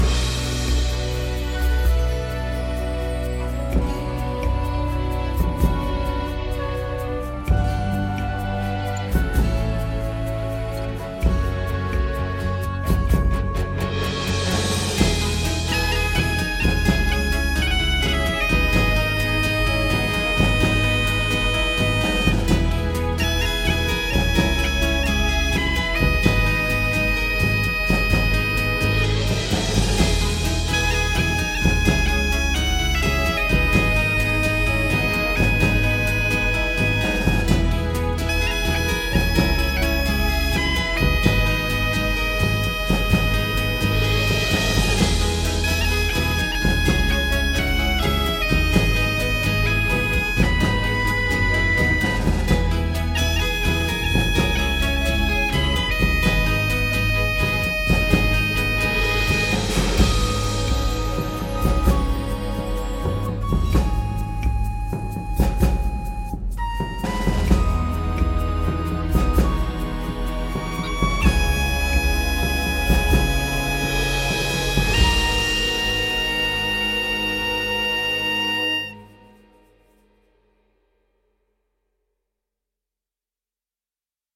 la gaita gallega reivindica nuestros orígenes